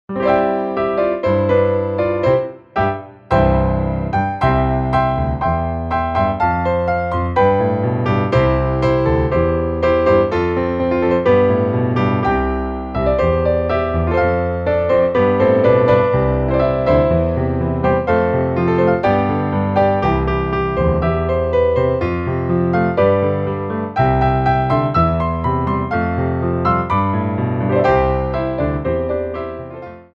Grands Battements
4/4 (16x8)